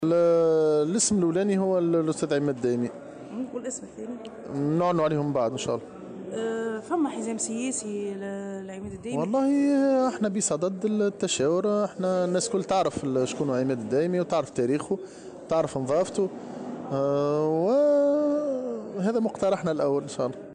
وأضاف اليوم في تصريح لمراسلة "الجوهرة أف أم" أن الحزب بصدد التشاور حول هذا "المُقترح الأول"، وفق قوله.